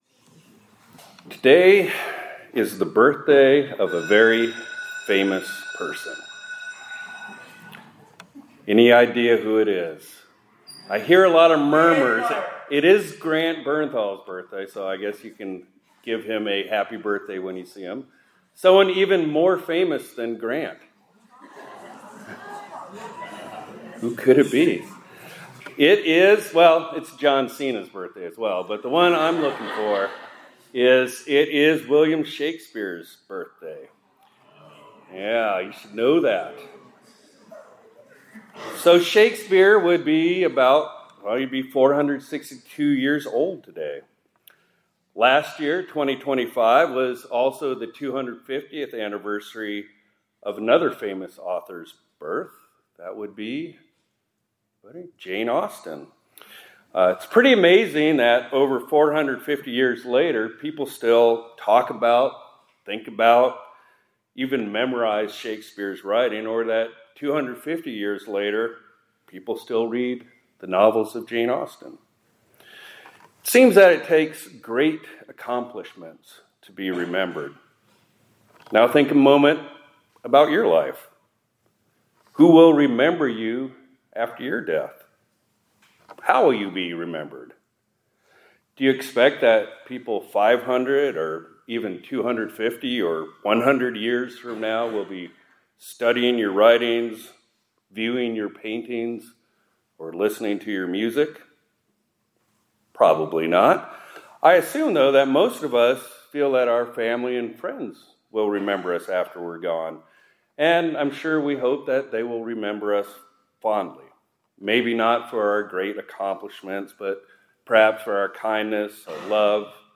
2026-04-23 ILC Chapel — “Lord, Remember Me”